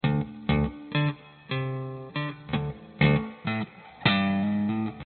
时髦的Bass Riff 1
描述：用电贝司（Fender Preci）弹奏时髦的拍击乐。
Tag: 贝斯 芬德 吉他 精确